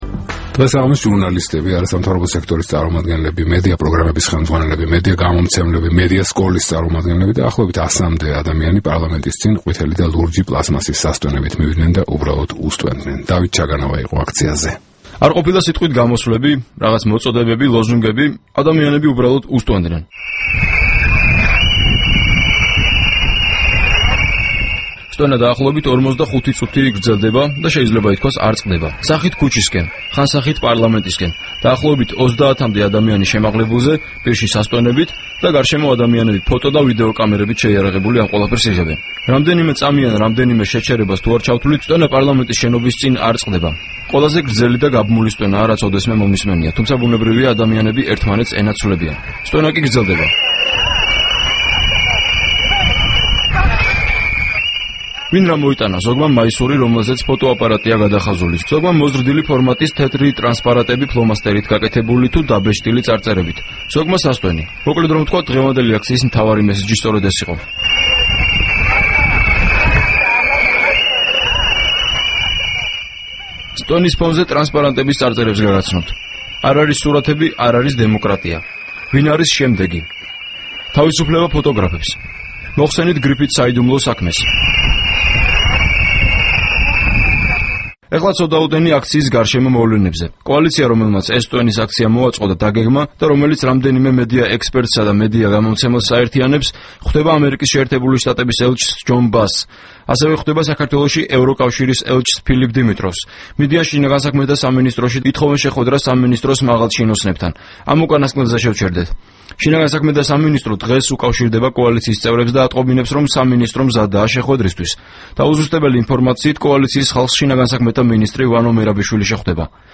45-წუთიანი სტვენა პარლამენტის წინ